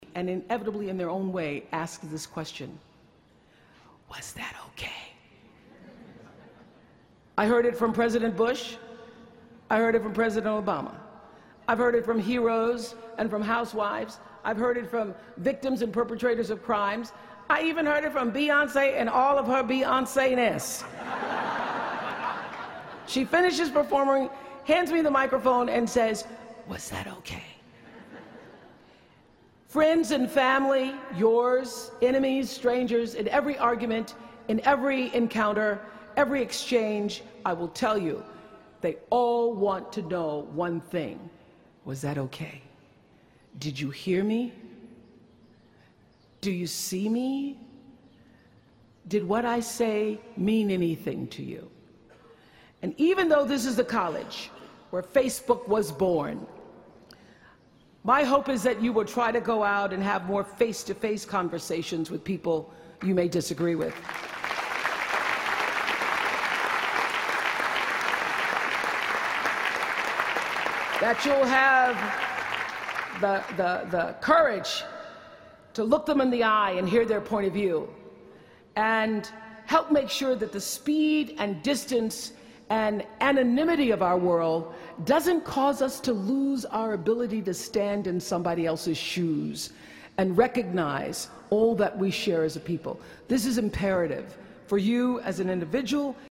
公众人物毕业演讲第365期:奥普拉2013在哈佛大学(14) 听力文件下载—在线英语听力室